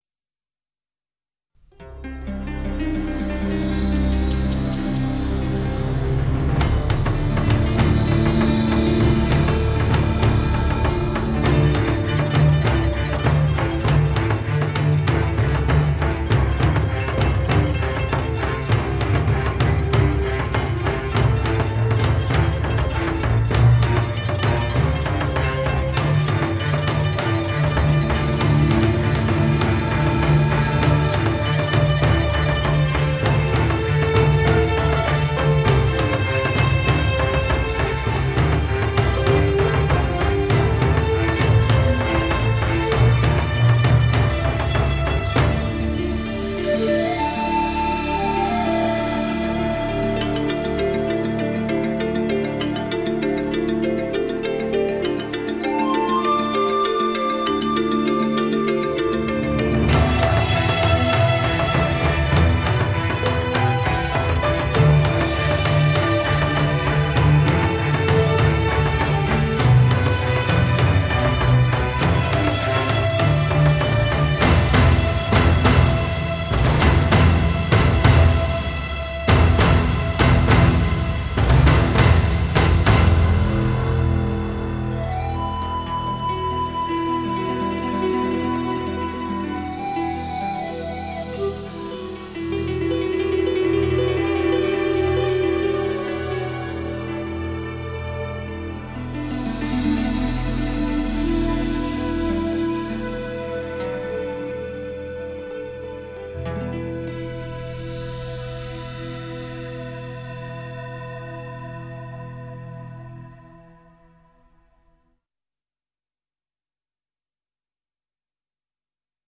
尤其是电子合成器的精彩表现，绝对可以媲美大型管弦乐团，让人品尝到一顿精彩的武侠盛宴。